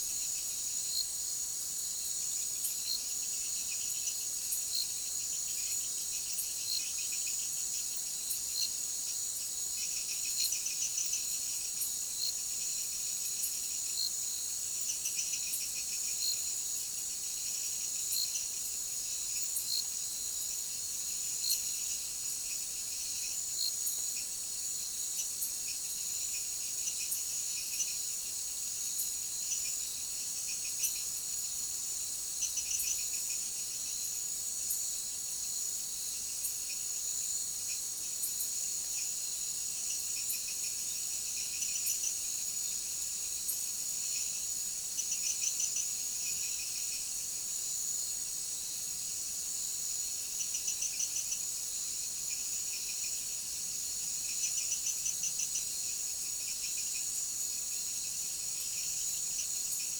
Anfibios
(Stryla sp)